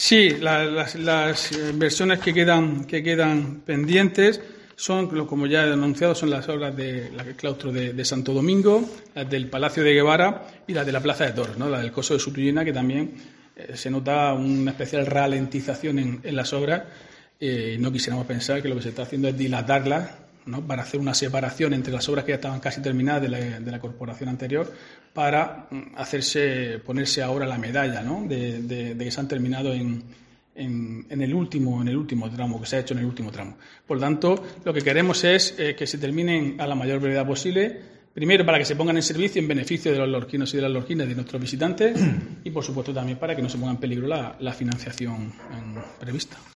Diego José Mateos, portavoz del PSOE